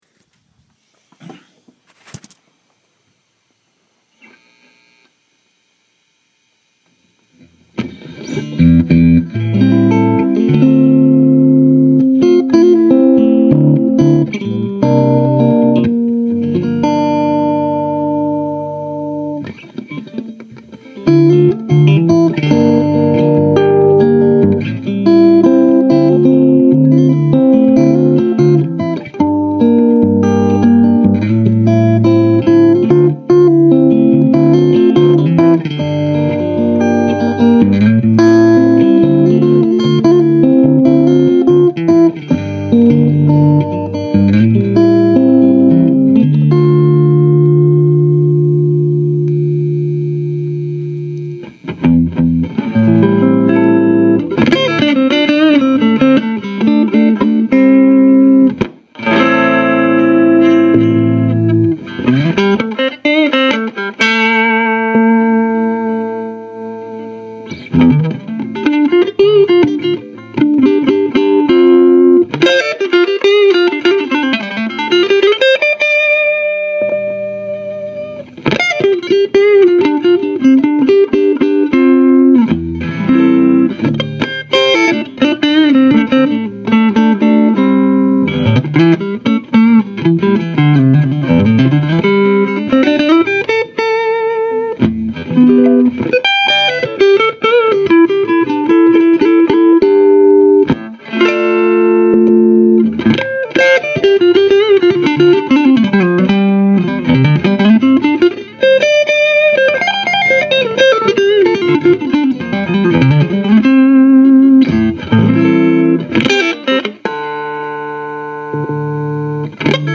Well, I dialed in a hotter bias and the amp is slightly more tolerant of the input but stil dropping out. You can hear it in the sample. When it thins out, I just touch the guitar cable or one of the pots and it comes right back.
This was recorded with my iPhone just laying on the carpet so the quality is what it is.